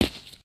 main Divergent / mods / Footsies / gamedata / sounds / material / human / step / test_asphalt01.ogg 6.1 KiB (Stored with Git LFS) Raw Permalink History
test_asphalt01.ogg